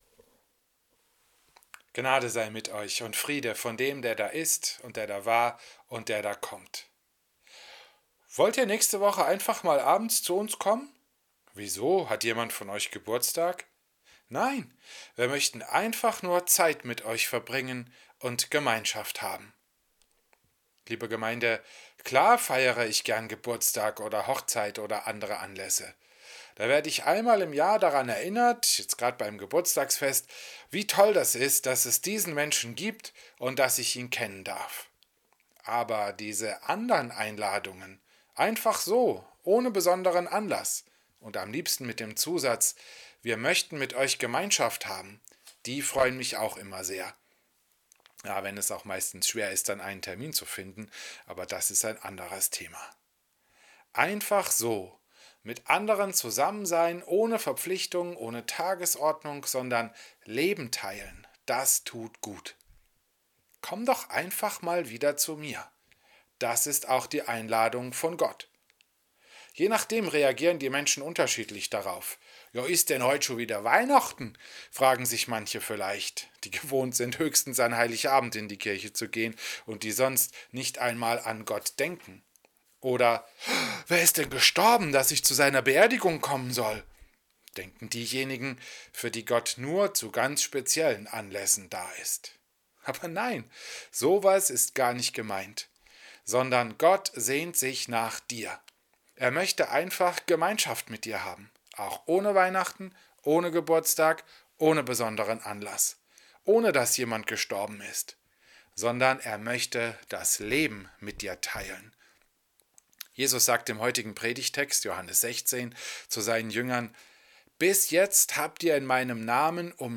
Predigt im Christus-Pavillon Volkenroda am Sonntag Rogate